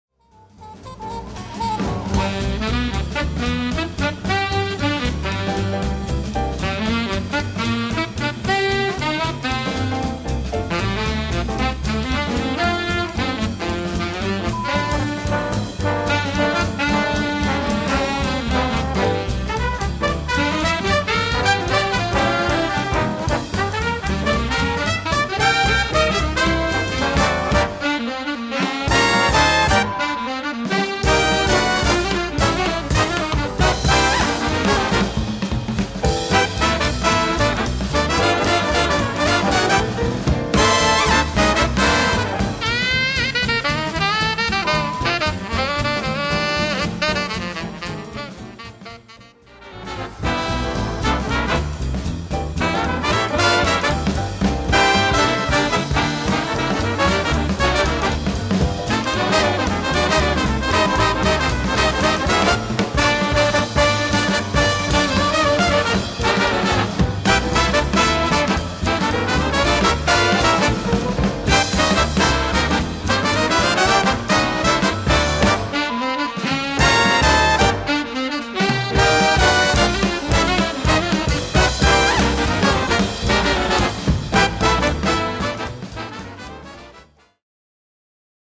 flag-waver to end all flag-wavers!
solos for piano and tenor